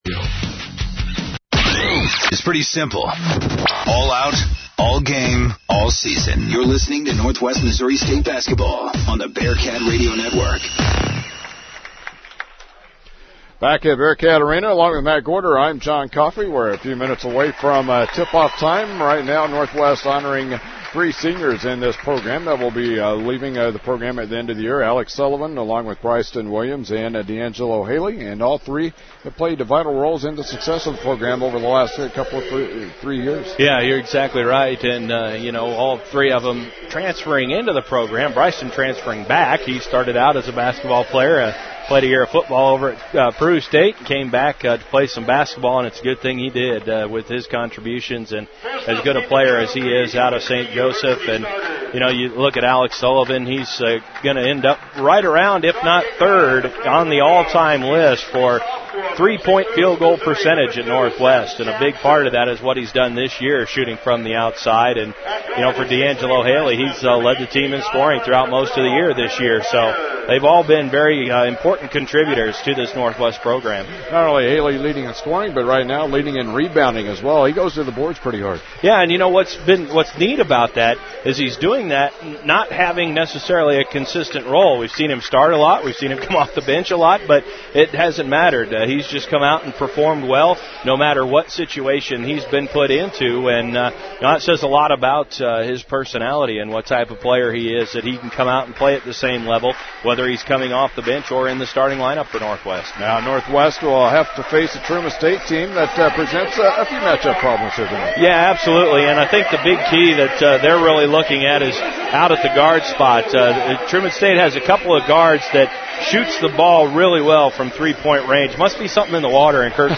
Broadcasts | Bearcat Radio Network | KXCV-KRNW
The Bearcat men's basketball team competes against Truman State in Maryville, Mo.
Local Sports